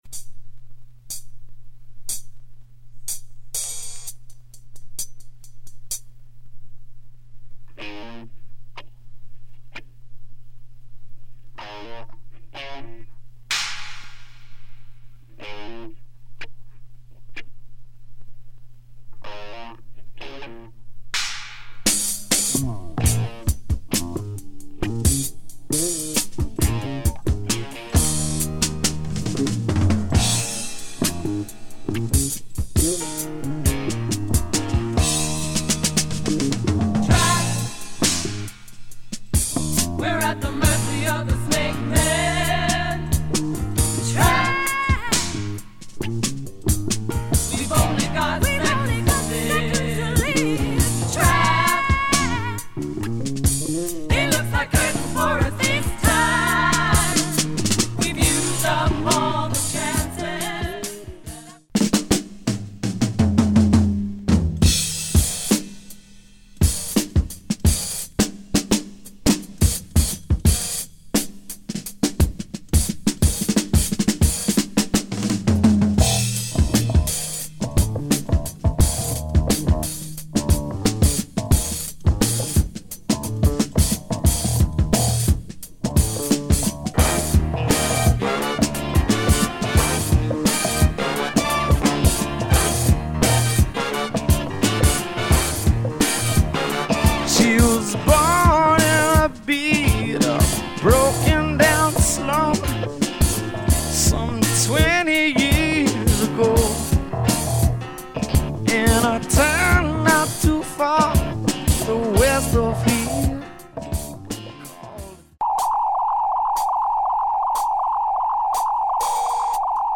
Nice psych soul tune
plus a killer drumbreak